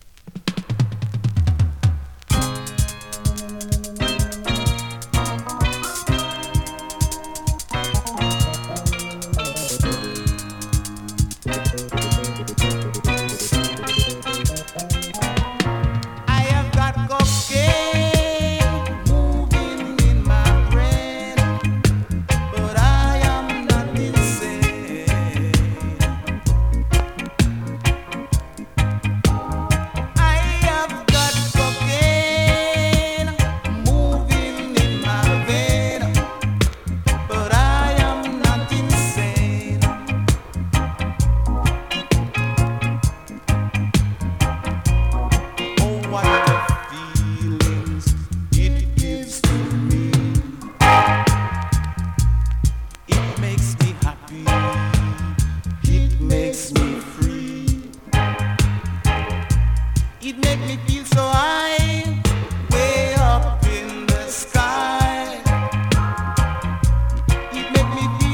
SKA〜REGGAE
スリキズ、ノイズ比較的少なめで